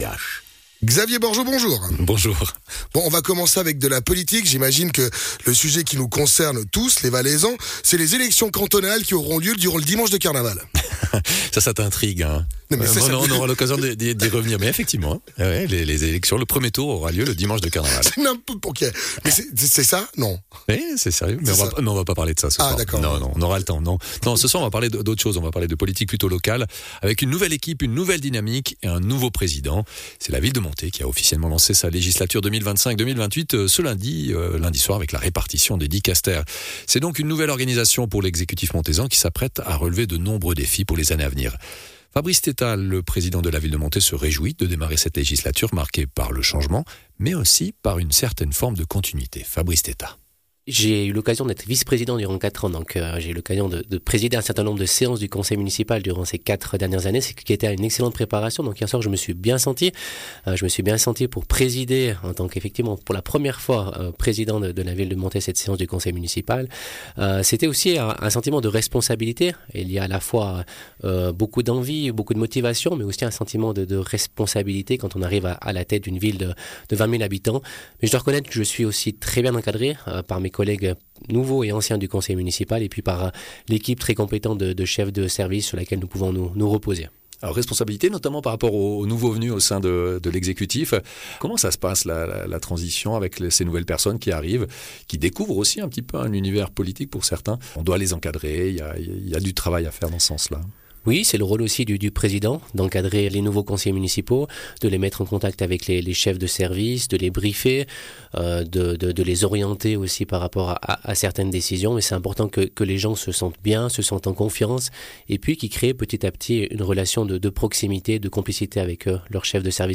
Intervenant(e) : Fabrice Thétaz, Président de la Ville de Monthey